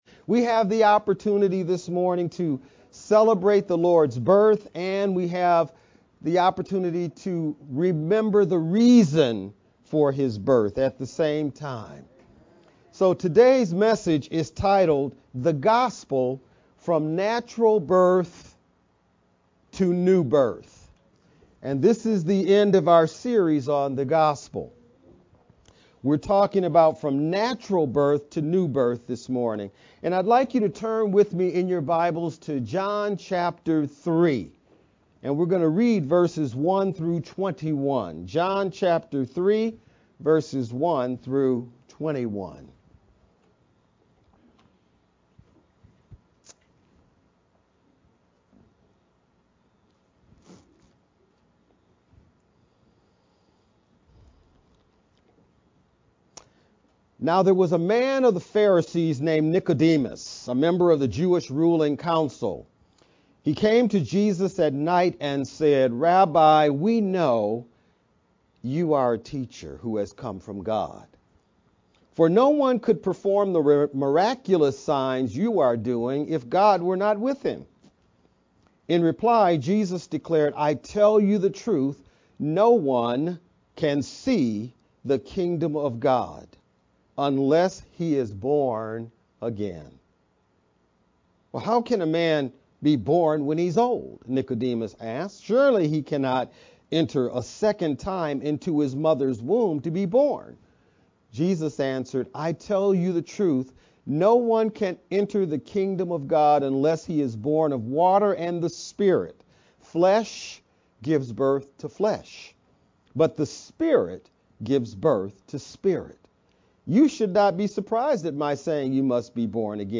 VBCC-Sermon-only-mp3-CD.mp3